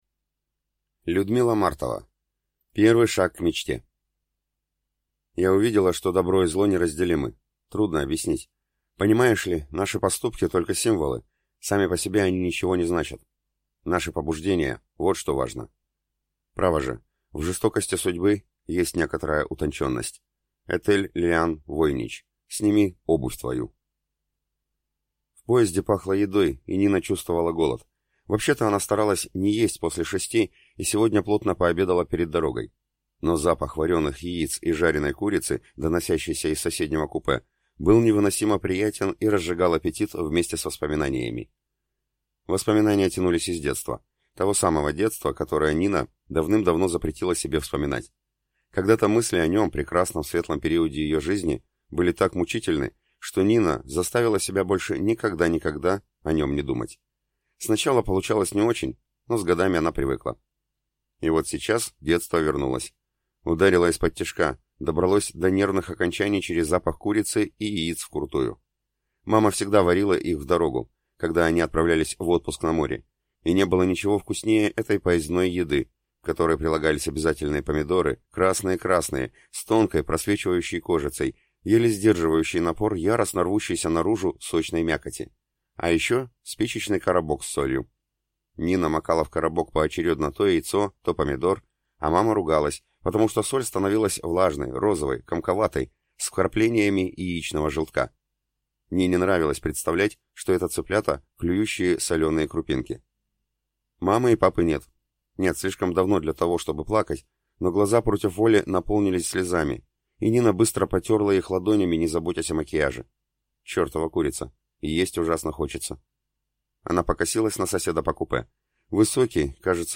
Аудиокнига Первый шаг к мечте | Библиотека аудиокниг